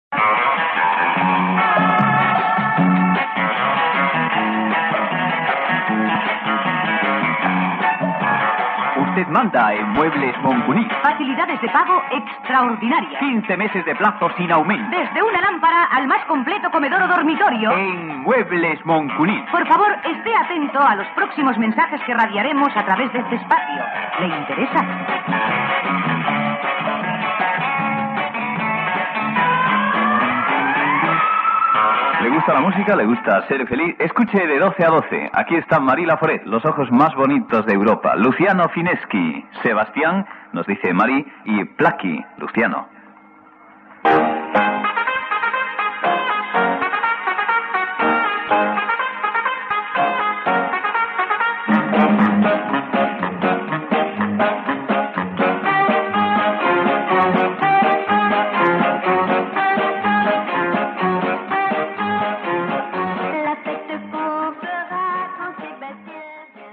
Publicitat, identificació del proframa i presentació d'un tema musical
Entreteniment